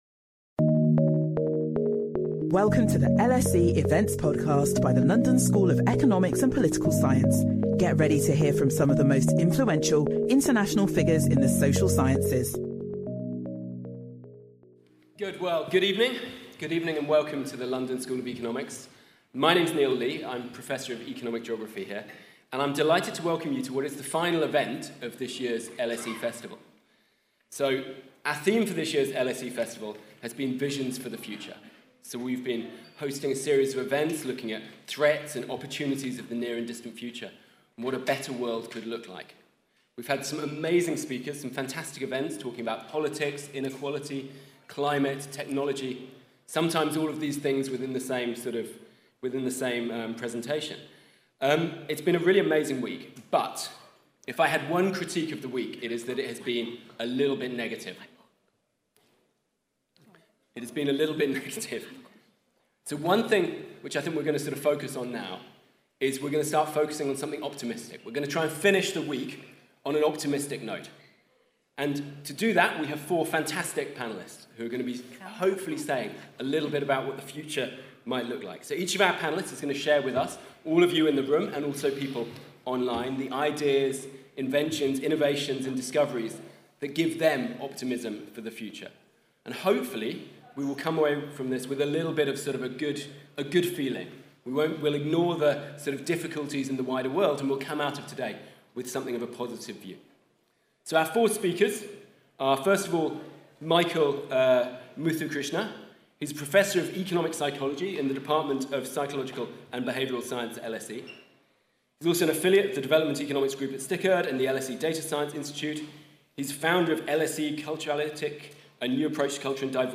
Where should we look for optimism about the future? Our final panel come together to share some of the ideas, innovations and discoveries that could shape the world to come for the better.